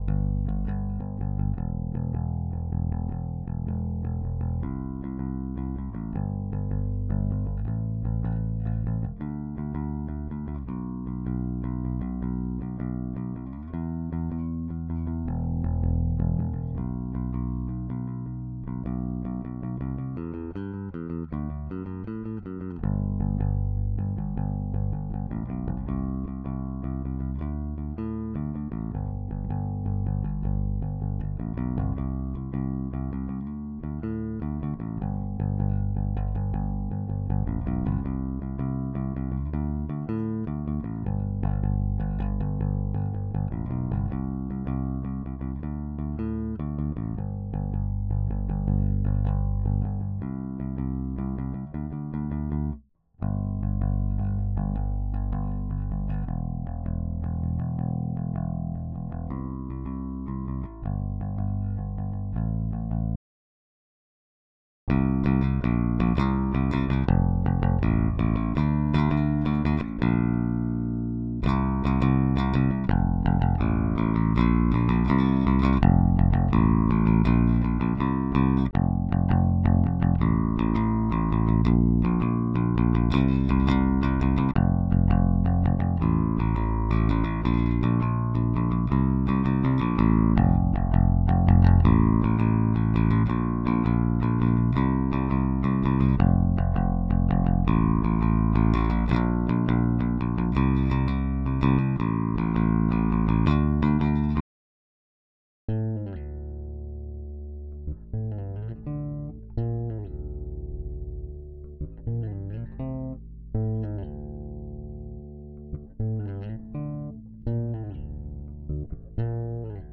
dibox_vs_line_input_raw.flac